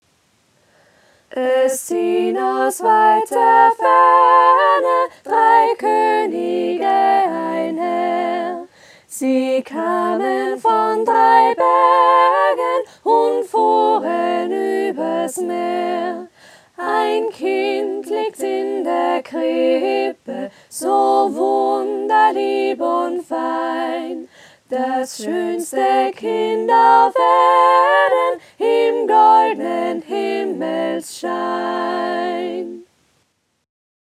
Sternsingerlied zum Mitsingen
sternsinger.mp3